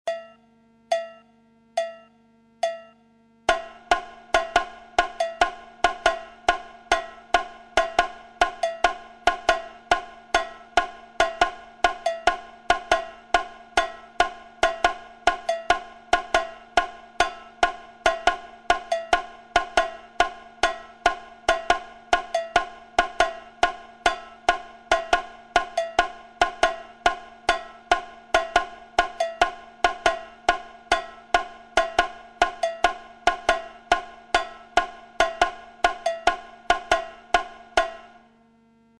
Le tambourim, frappé avec une baguette va faire différentes figures que l'on va retrouver dans la rythmique de la guitare suivant des schémas bien précis et qui articulés avec le chant détermine les appuis du phasé et de l'harmonie .
Le tambourim figure 1
Figure de base du tambourim dans la bossa, regardez les accents par rapport au Surdo.